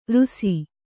♪ rusi